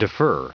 Prononciation du mot defer en anglais (fichier audio)